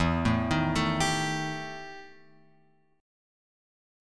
EmM7bb5 chord {0 0 1 2 x 3} chord